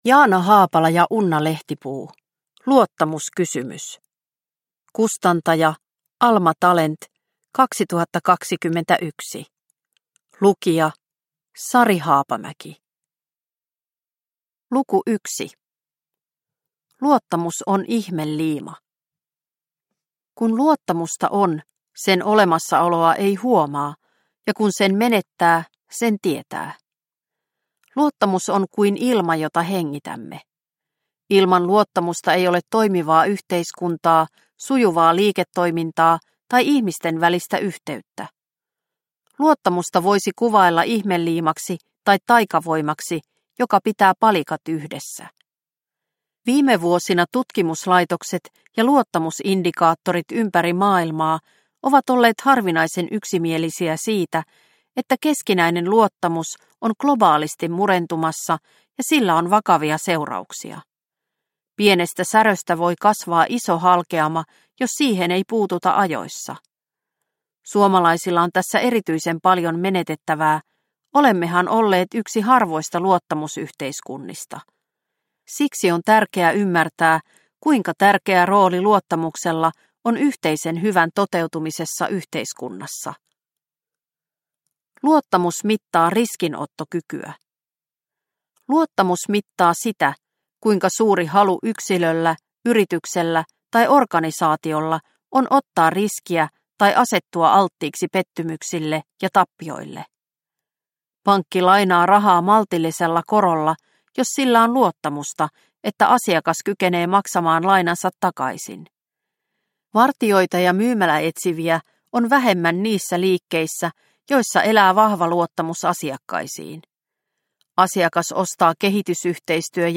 Luottamuskysymys – Ljudbok – Laddas ner